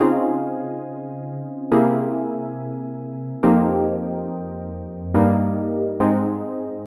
罗德爵士乐和弦的进展
它是由Lounge Lizard VST、Vulf Compressor和Retro RE20组成的黑胶噪音和LFO。
Tag: 72 bpm Jazz Loops Piano Loops 1.12 MB wav Key : F